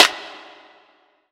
Snare [Pharell].wav